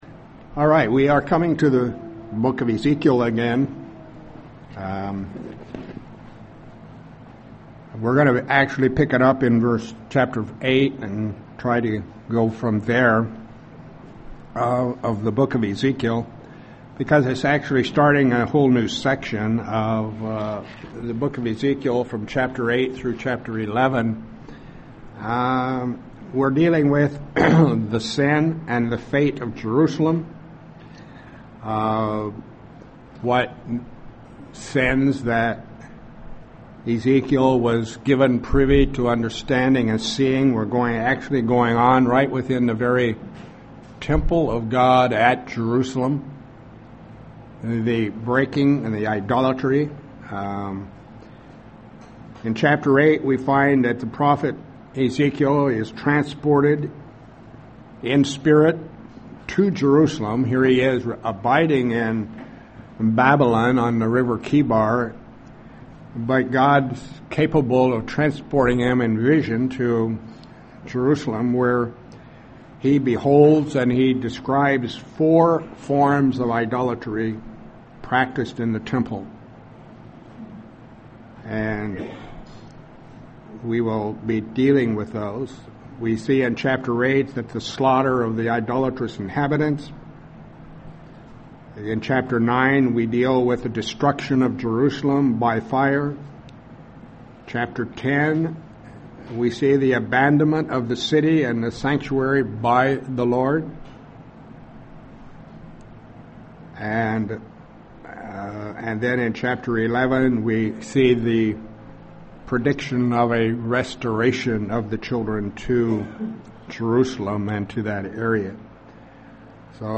A Bible study on Ezekiel chapters 8 and 9.